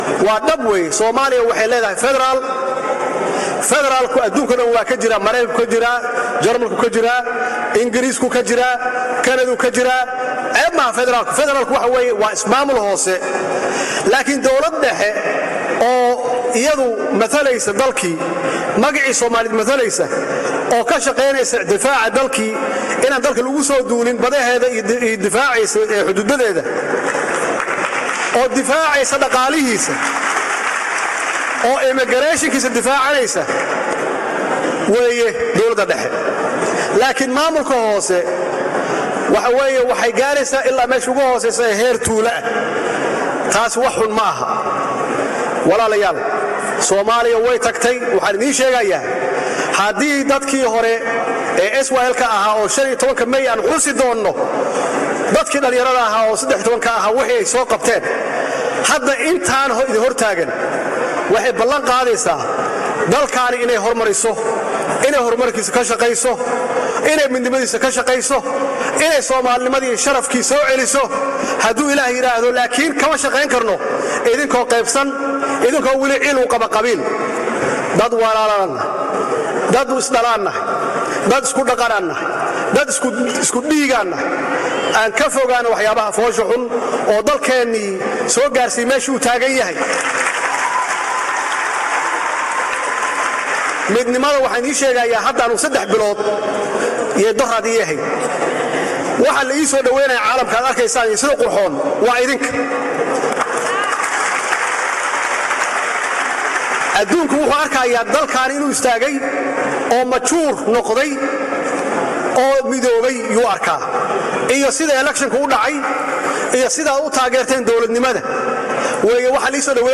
Hadalka kasoo yeeray madaxweyne Axmed Madoobe ayaa waxaa isla xafladii London ugu jawaabay madaxweynaha JFS mudane Maxamed Cabdulaahi Farmaajo oo sheegay in Soomaaliya ay qaadatay nidaamka Fadaraalka uuna yahay mid u fiican Soomaaliya.
Dhagayso madaxweynaha DFS Maxamed Cabdulaahi Farmaajo oo sheegay in Soomaaliya qaadatay Fadaraal uuna fiican yahay